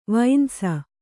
♪ vainsa